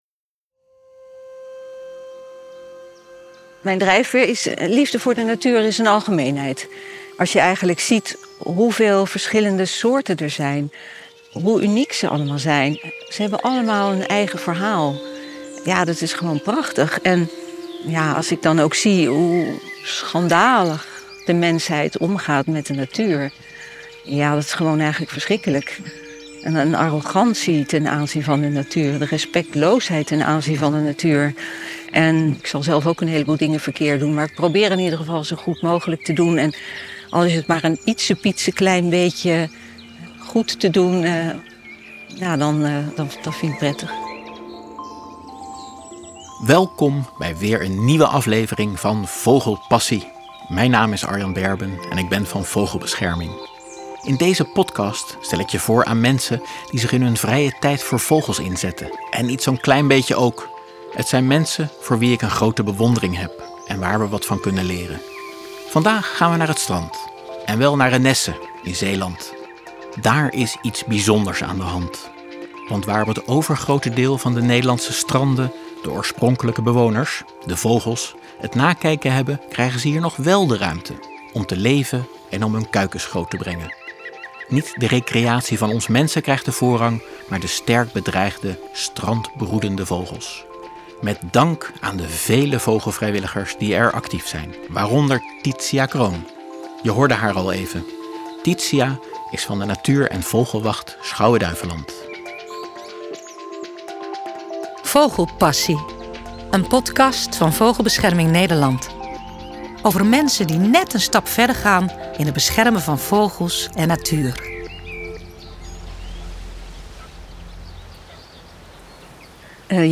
vertelt op het strand van Renesse hoe zij en haar mede-vrijwilligers te werk gaan. Ze geven de strandbroeders er ruimte om hun leven te leiden en worden daar steeds succesvoller in.